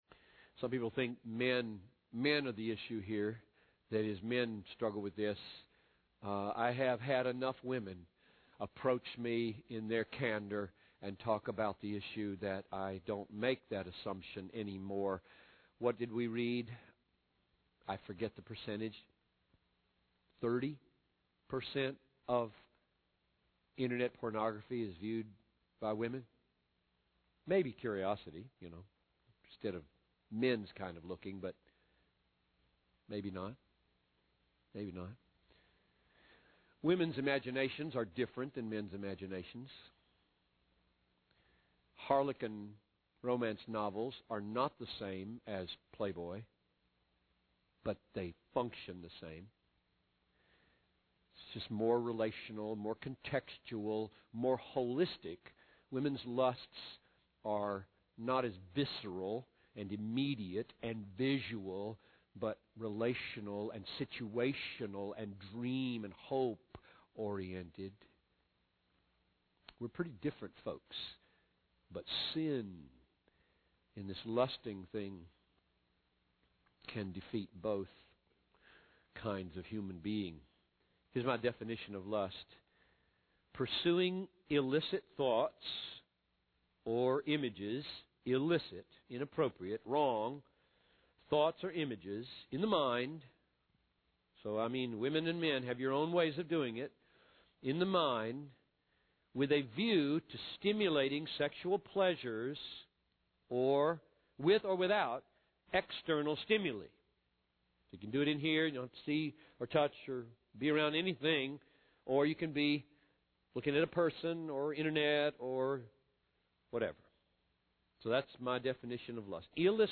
In this sermon, the preacher emphasizes the importance of building a strong foundation of knowledge and allegiance to God and Christ. He believes that by immersing oneself in the fullness of biblical revelation, individuals can overcome the dangers of lust and find true freedom.